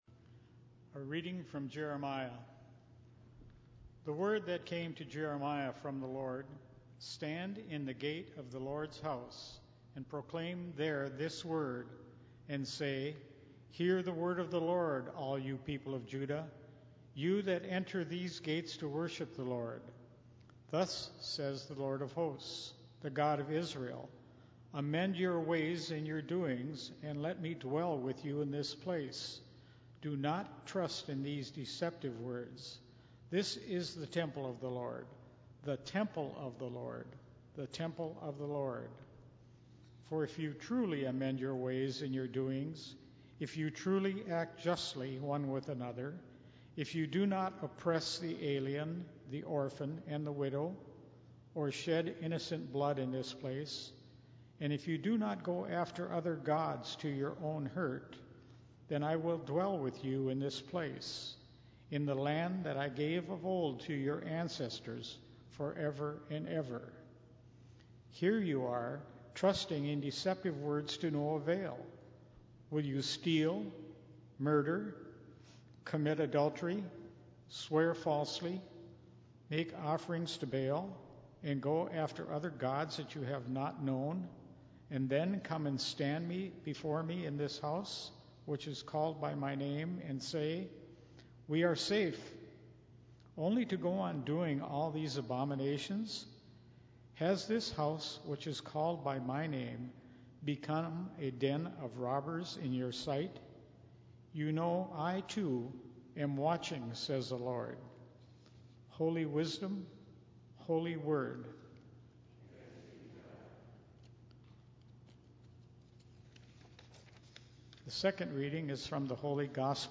Minnetonka Livestream · Sunday, June 19, 2022 9:30 am